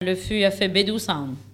Localisation Sallertaine
Locutions vernaculaires